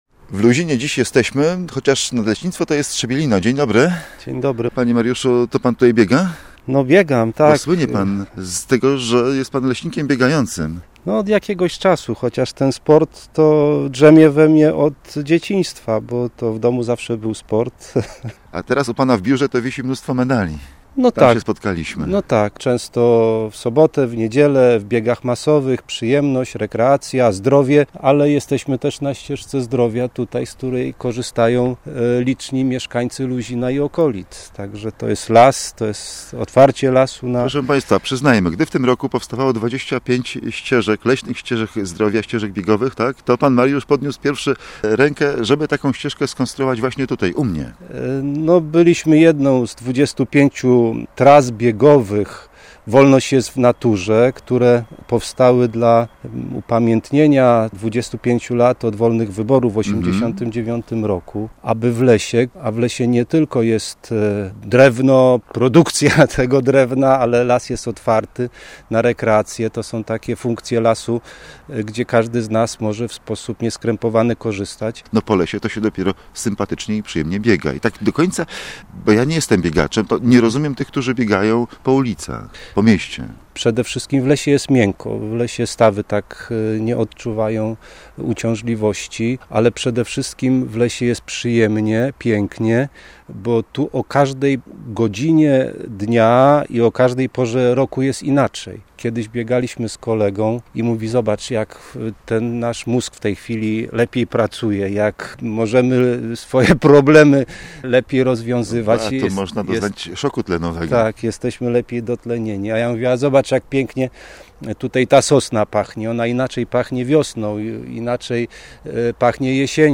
Nagrywając rozmowę spotkaliśmy też trenującego w lesie ultramaratończyka